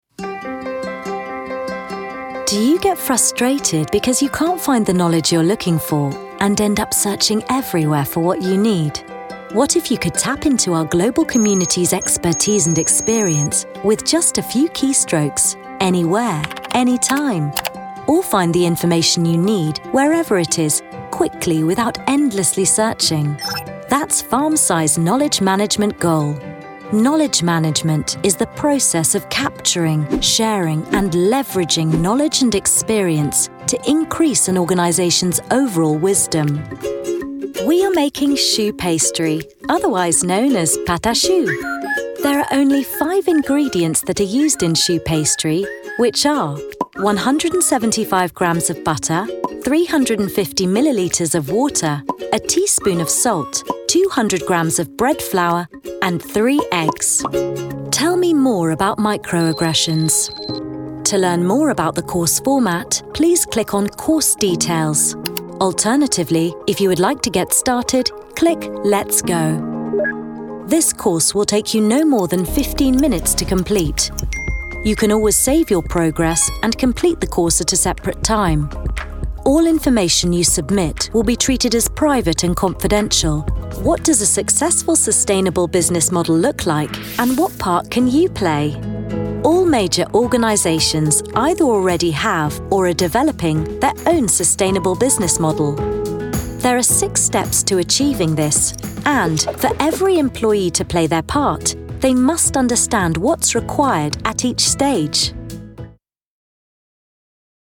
Professional British Voiceover Artist & live event Voice of God with a clear, bright and reassuring voice
Sprechprobe: eLearning (Muttersprache):
She has a naturally modern RP accent and plays in the Teens - 30s age range.